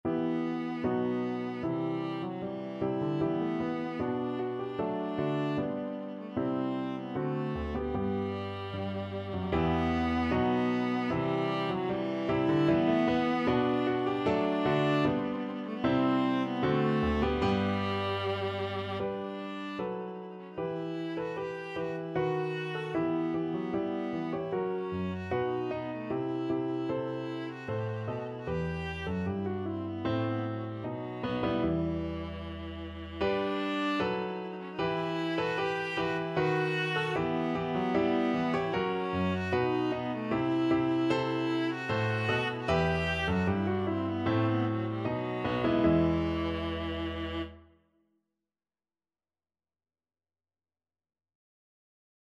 Viola
E minor (Sounding Pitch) (View more E minor Music for Viola )
2/2 (View more 2/2 Music)
Steadily =c.76
Classical (View more Classical Viola Music)